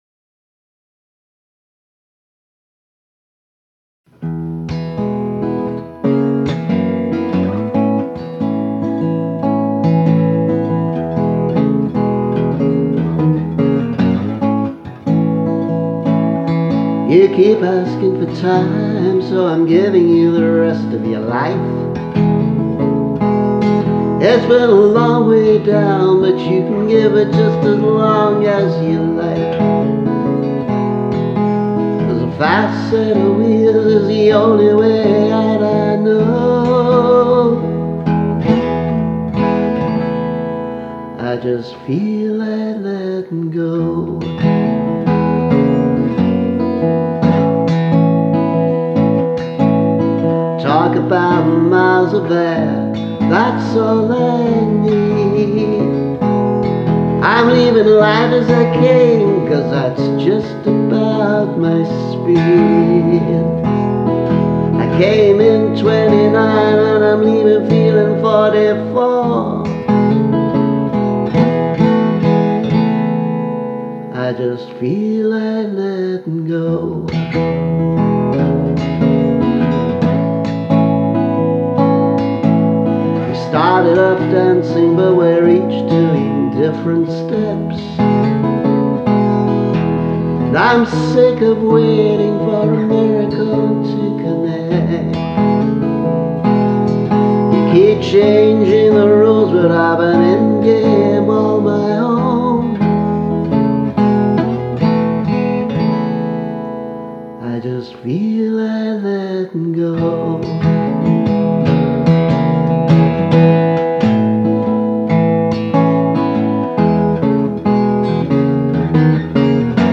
letting go [demo] 2019
remastered-letting-go.mp3